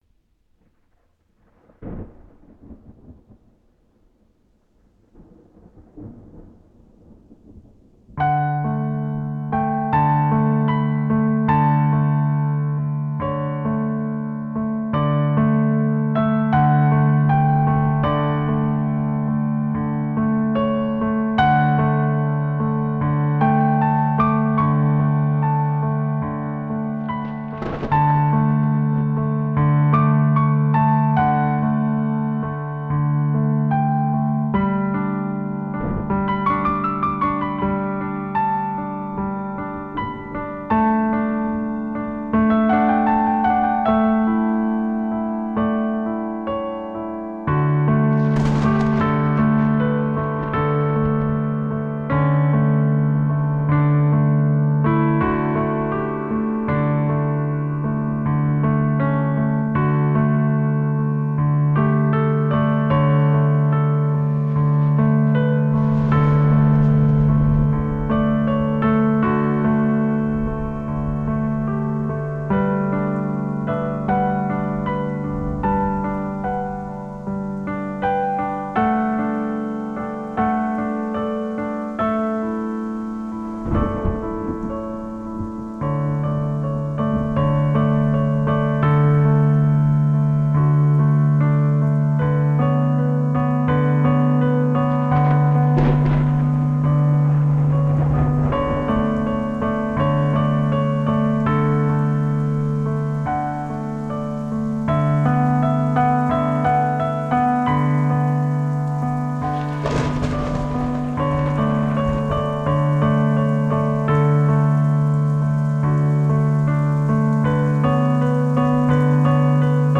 proyecto individual y minimalista